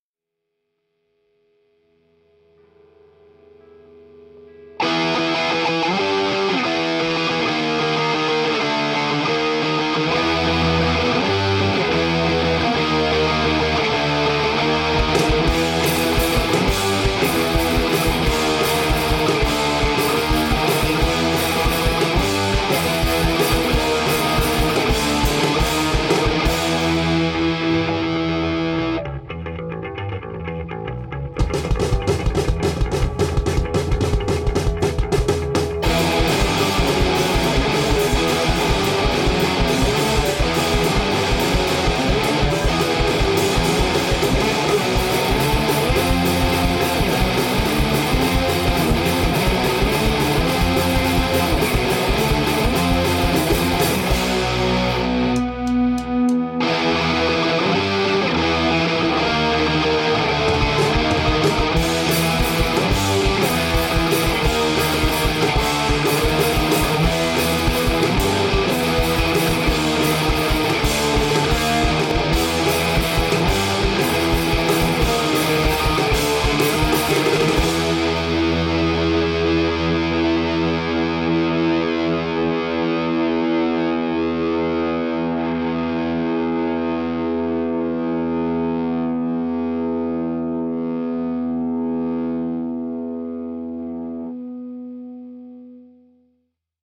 DIY Hardcore Punk band from Ioannina, Greece
κιθάρα-φωνητικά
drums-φωνητικά